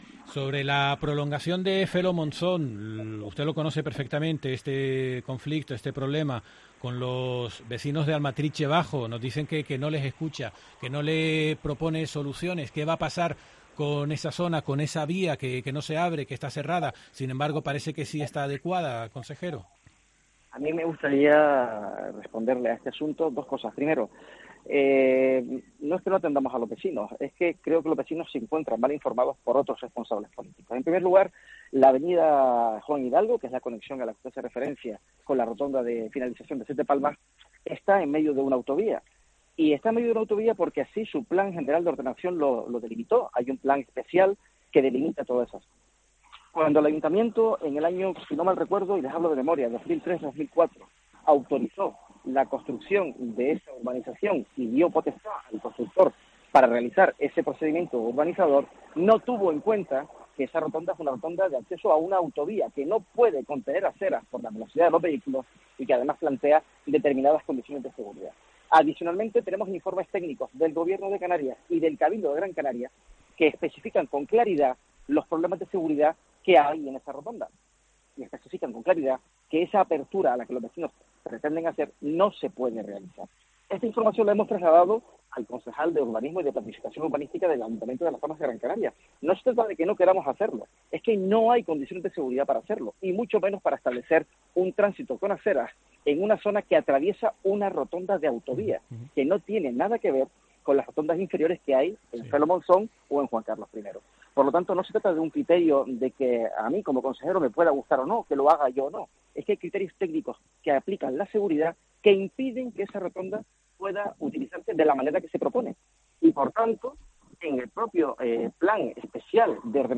Miguel Angel Pérez del Pino, consejero de obras públicas, infraestructuras, transporte y movilidad del Cabildo